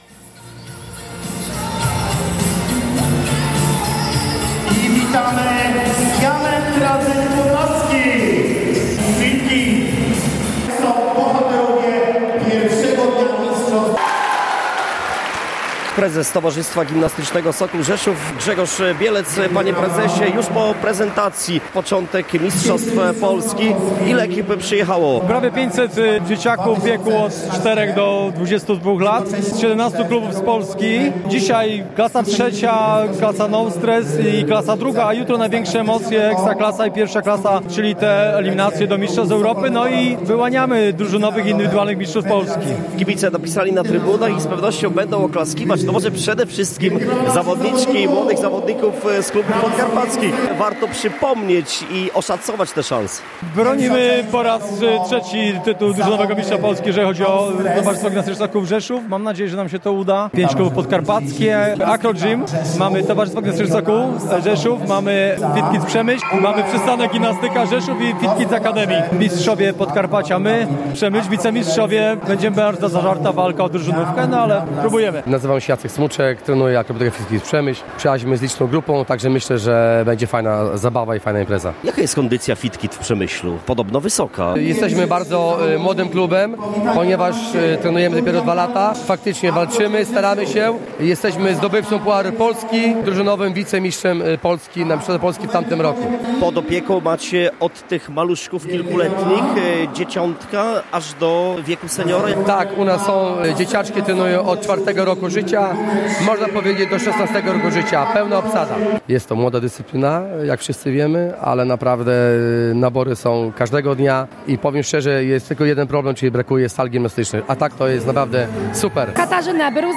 Sport • W sobotę i niedzielę trwały w Rzeszowie mistrzostwa Polski w dynamicznie rozwijającej się dyscyplinie sportu, odmianie akrobatyki sportowej.